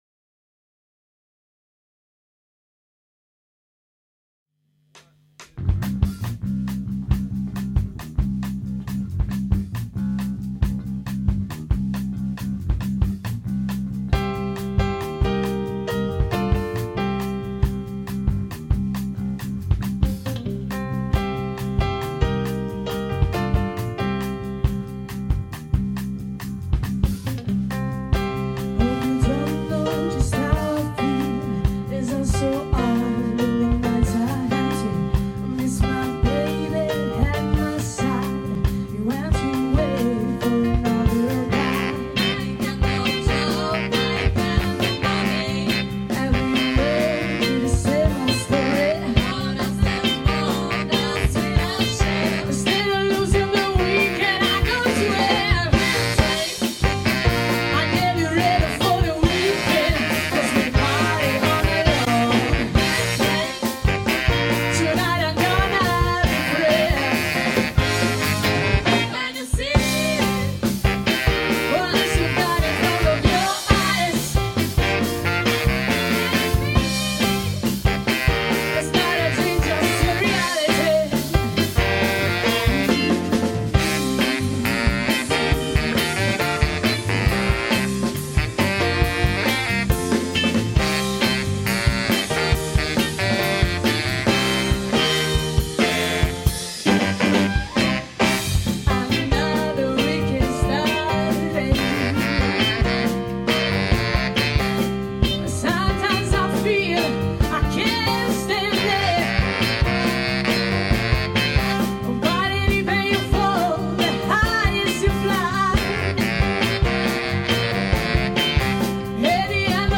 Luogo esecuzioneBologna
GenereBlues / Soul